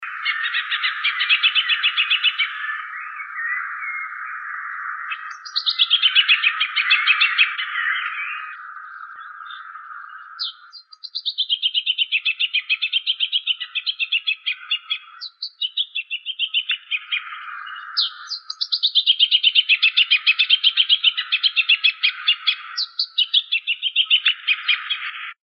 Southern Yellowthroat (Geothlypis velata)
Voz de respuesta al P.B.
Sex: Male
Location or protected area: Reserva Ecológica Costanera Sur (RECS)
Condition: Wild